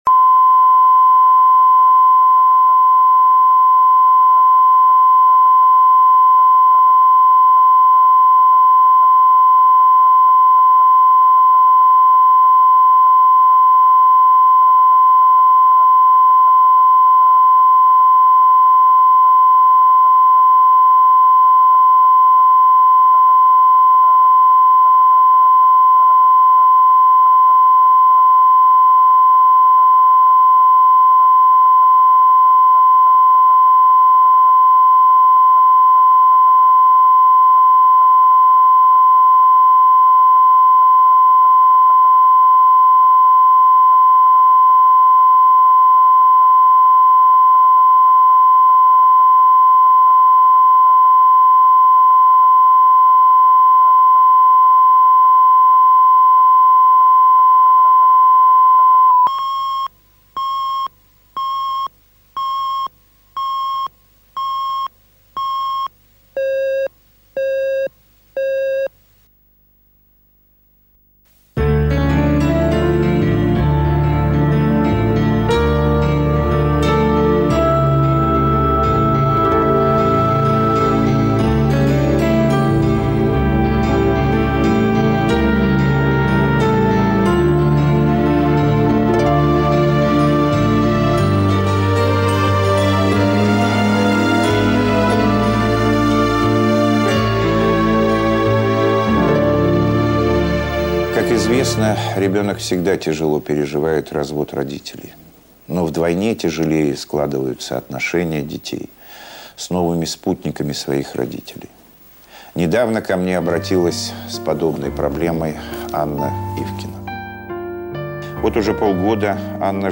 Аудиокнига Мачеха